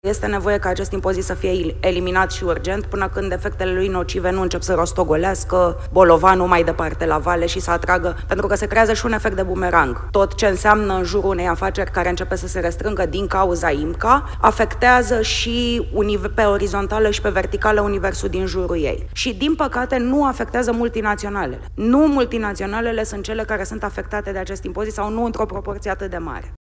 în dezbaterea „Bugetul și Fiscalitatea 2026”, găzduită de Academia de Studii Economice